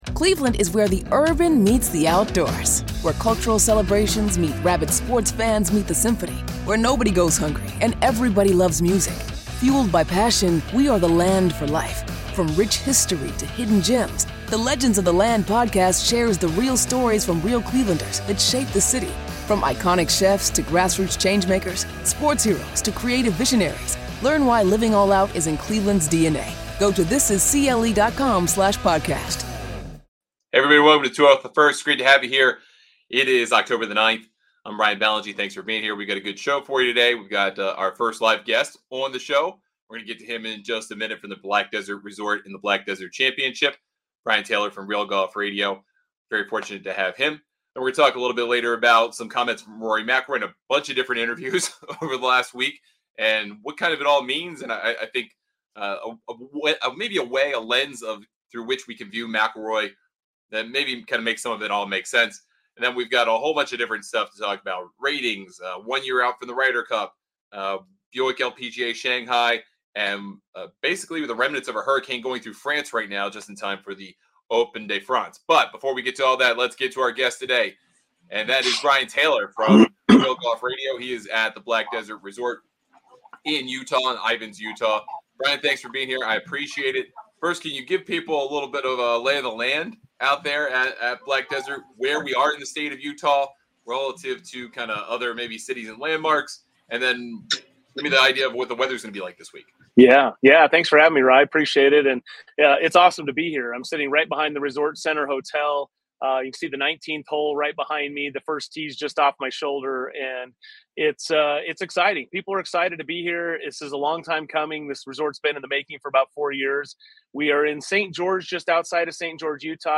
LIVE from Black Desert, Rory McIlroy speaks and more